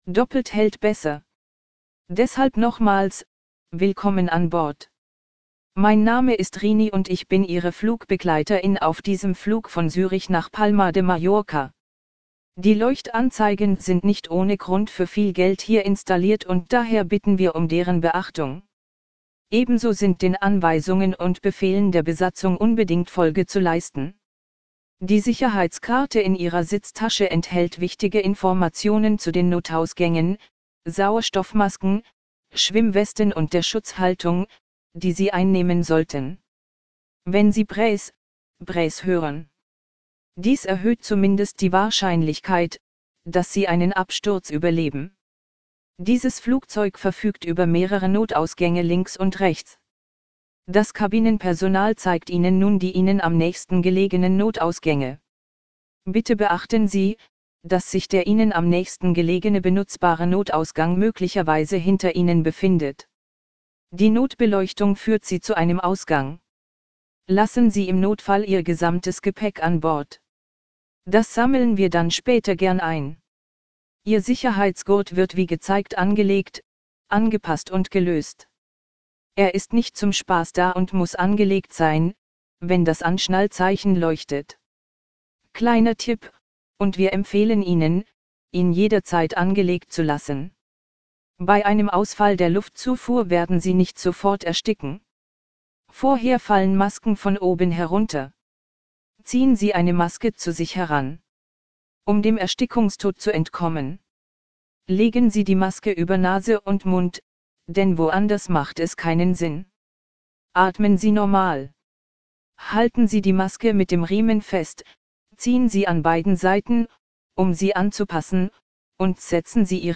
SafetyBriefing.ogg